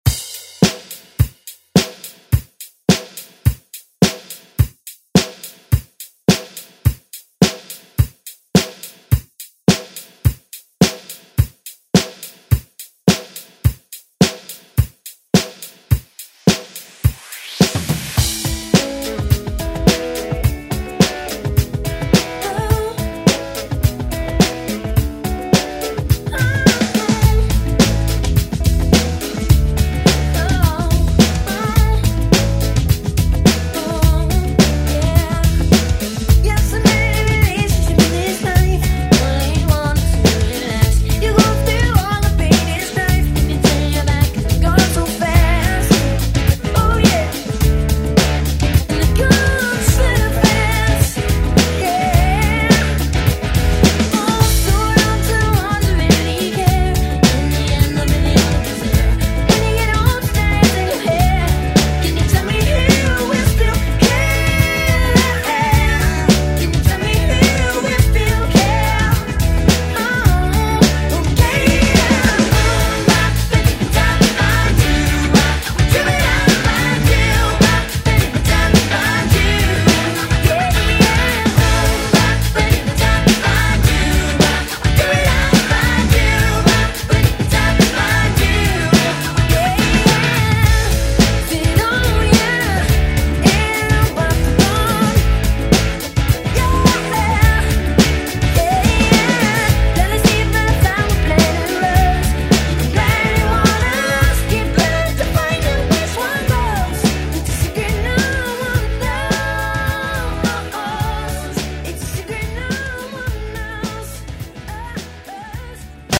Electronic Pop Music Extended ReDrum
121 bpm
Genres: 80's , RE-DRUM